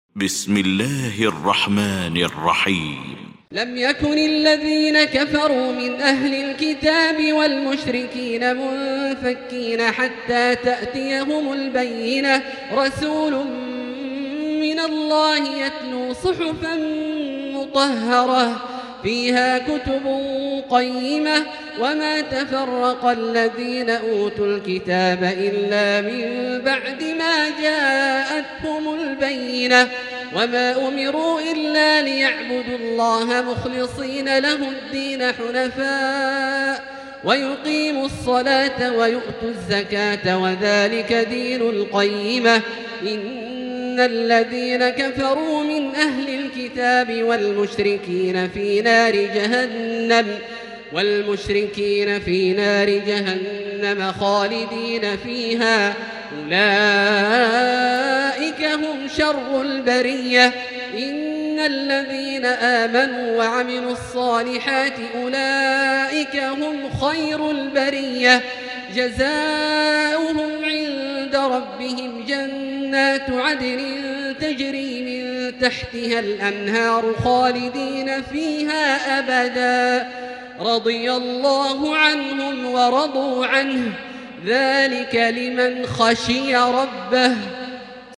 المكان: المسجد الحرام الشيخ: فضيلة الشيخ عبدالله الجهني فضيلة الشيخ عبدالله الجهني البينة